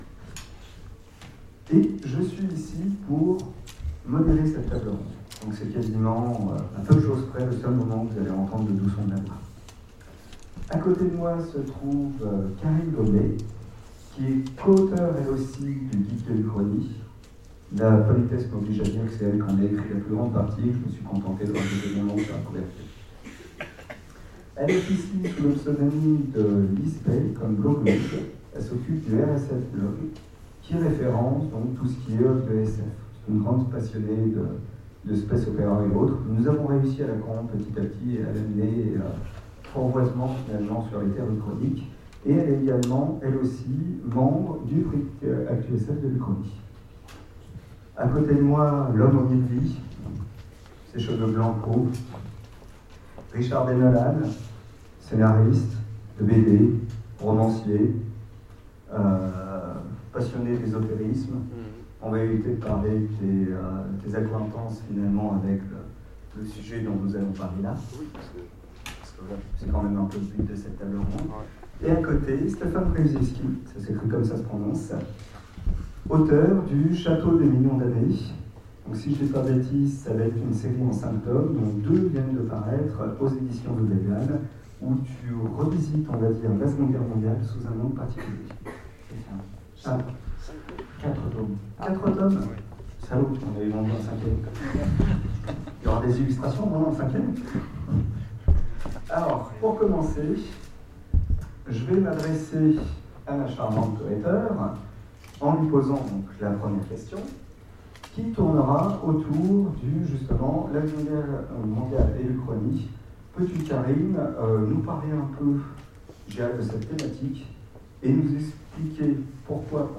Sèvres 2015 : Conférence Les 2es guerres mondiales alternatives